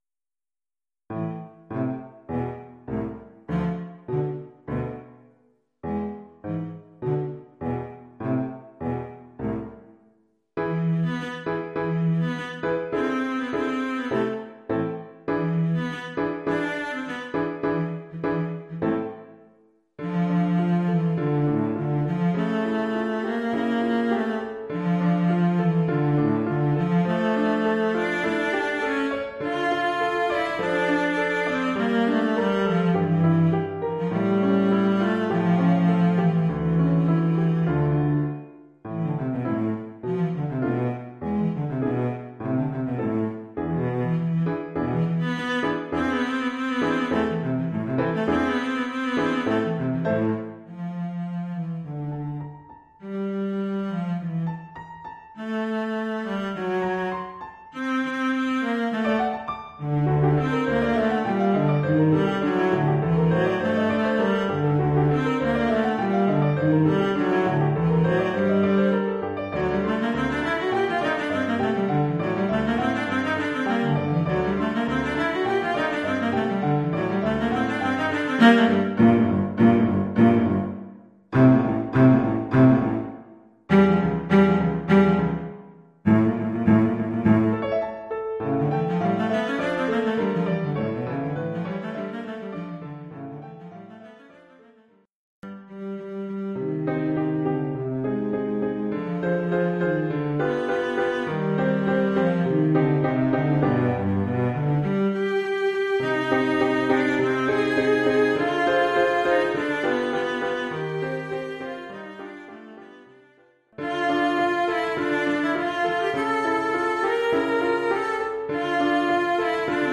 Oeuvre pour violoncelle avec accompagnement de piano.
avec accompagnement de piano".